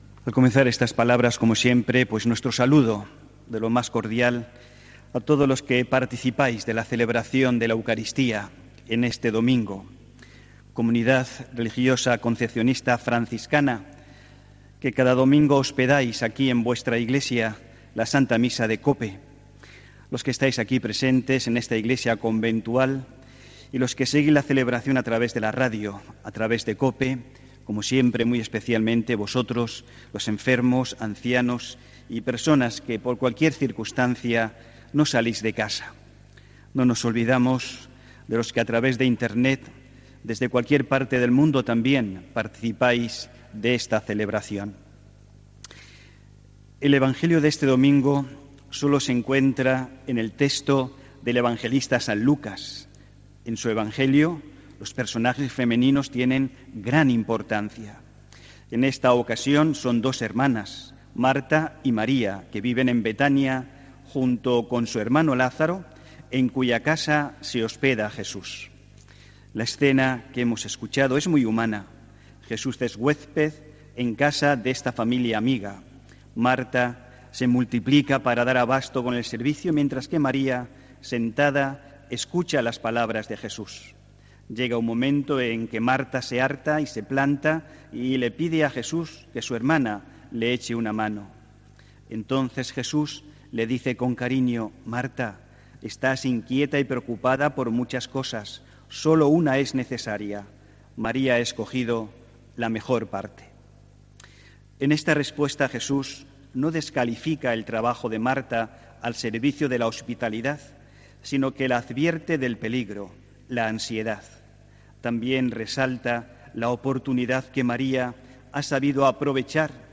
HOMILÍA 21 JULIO 2019